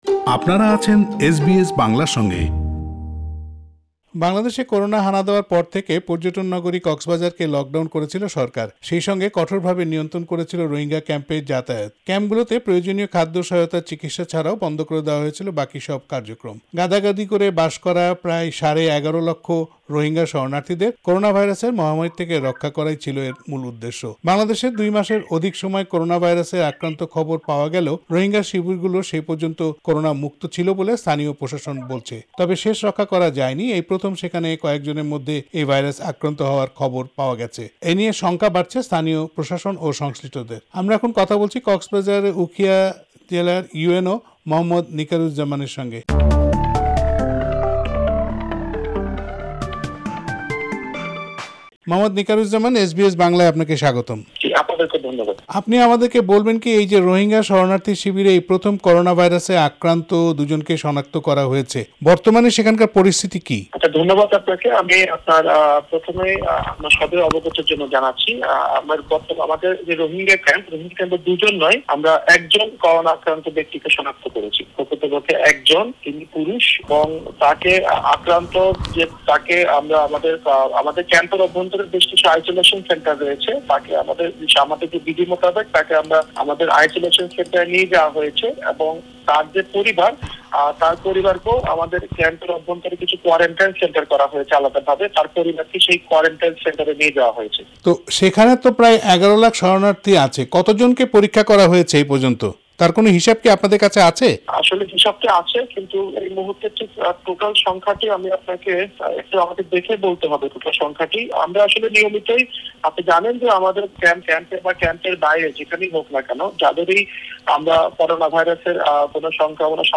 প্রতিবেদনটি বাংলায় শুনতে উপরের অডিও-প্লেয়ারটিতে ক্লিক করুন।